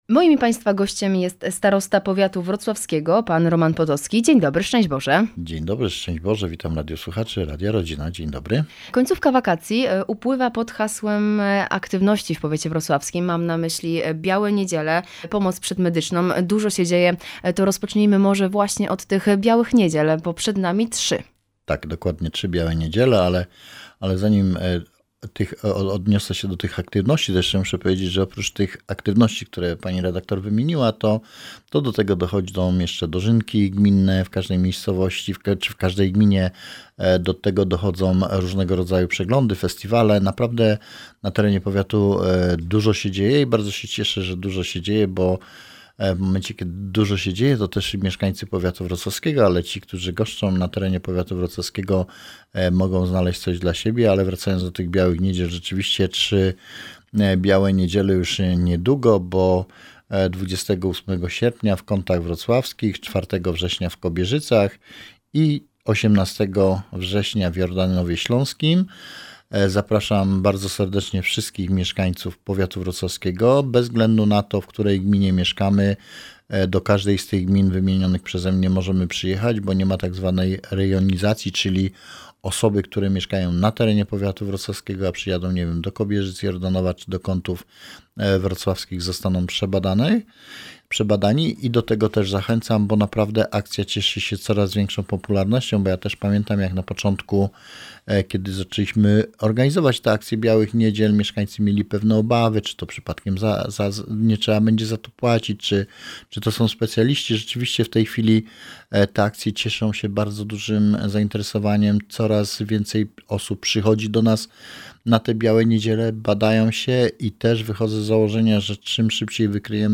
Białe Niedziele w Powiecie Wrocławskim, dożynki, aktualności drogowe – rozmawiamy ze starostą Romanem Potockim - Radio Rodzina